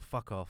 Voice Lines / Dismissive
fuck off.wav